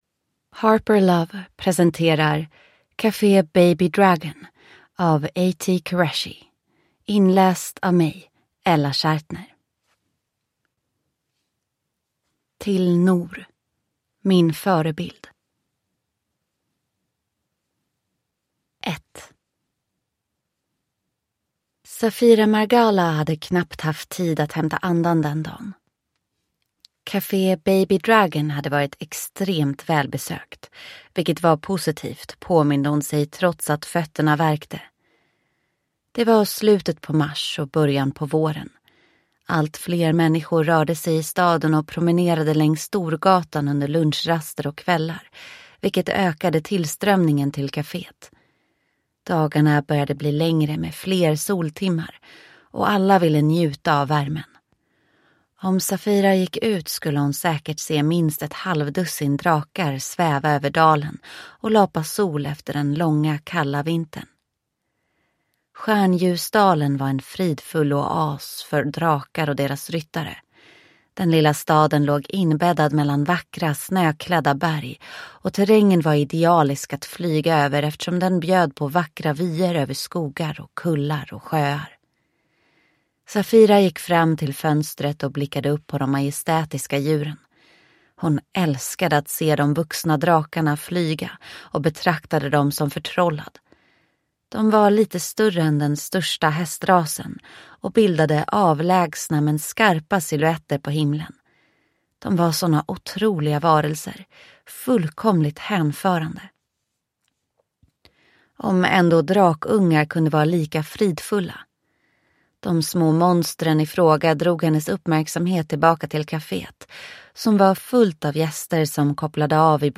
Kafé Baby Dragon – Ljudbok